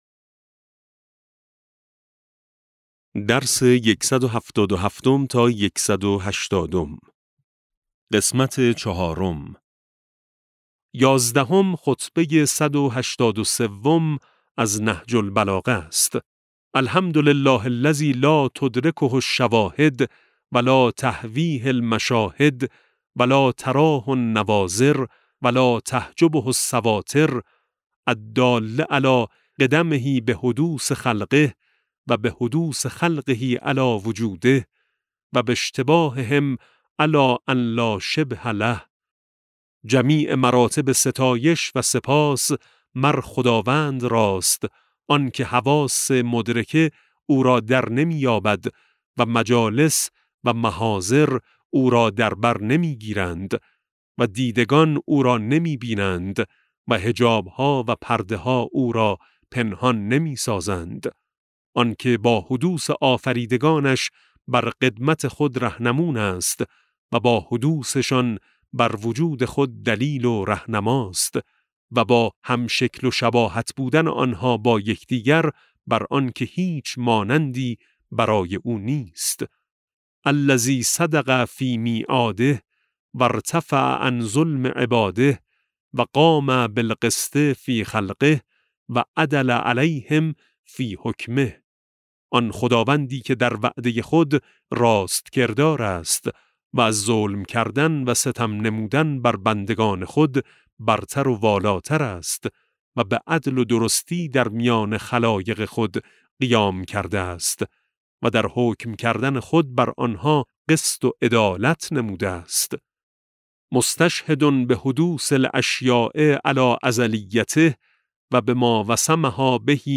کتاب صوتی امام شناسی ج12 - جلسه15